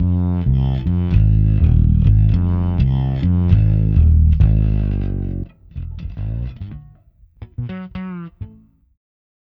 Ala Brzl 1 Bass-F.wav